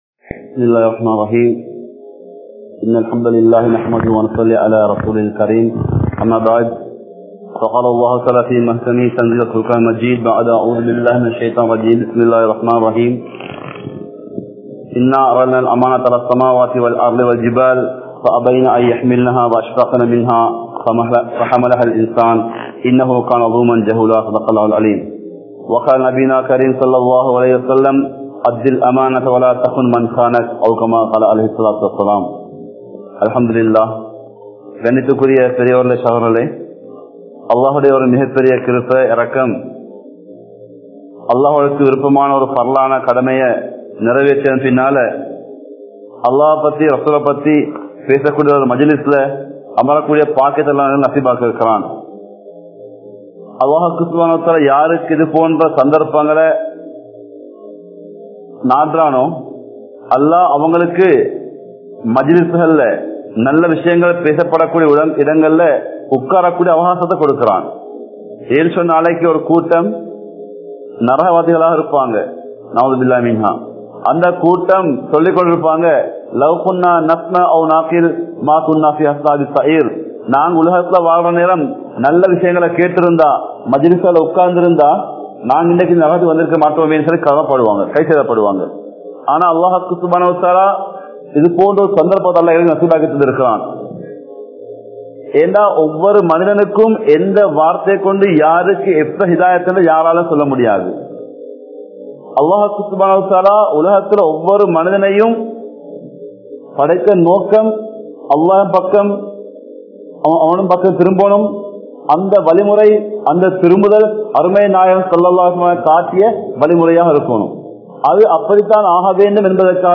Dheen Oru Amaanitham (தீண் ஒரு அமானிதம்) | Audio Bayans | All Ceylon Muslim Youth Community | Addalaichenai